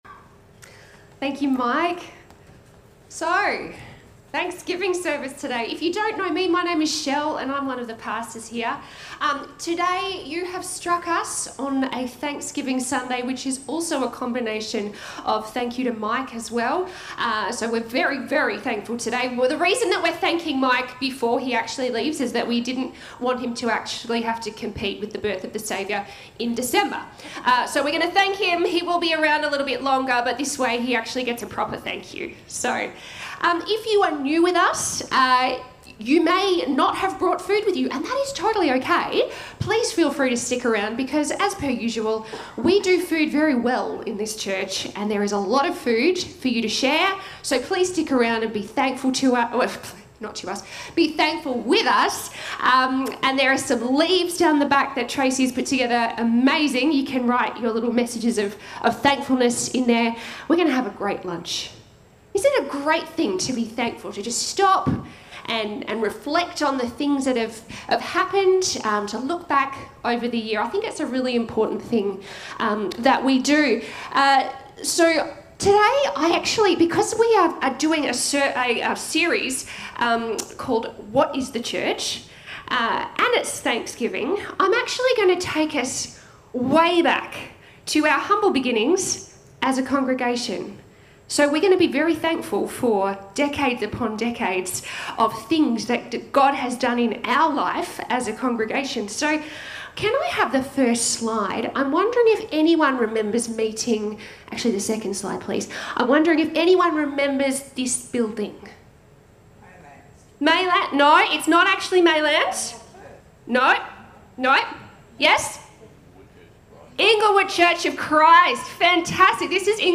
A message from the series "We are the Church."
She takes a look at the roots of Inglewood Community Church, and talks about some exciting things for the future. As it is thanksgiving Sunday, we finish off with a responsive prayer thanking God for His faithfulness through it all.